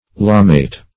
Lamaite \La"ma*ite\n.